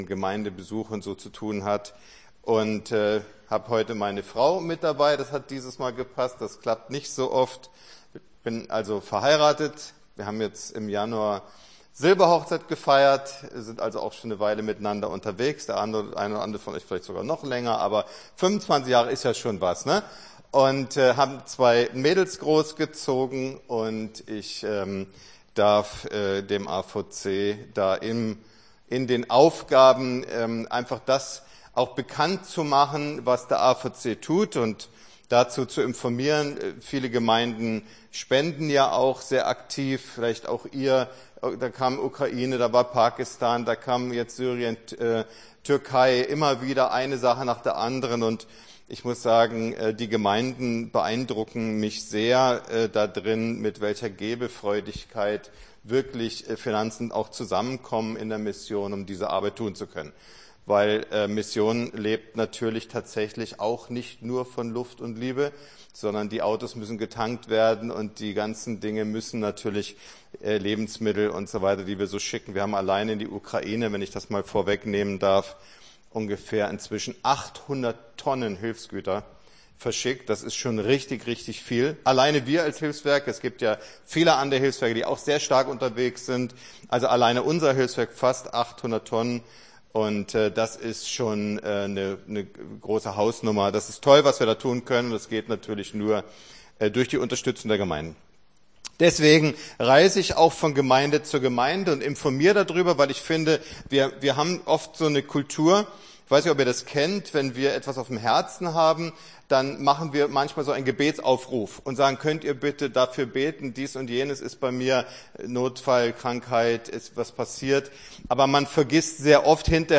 Gottesdienst 23.04.23 - FCG Hagen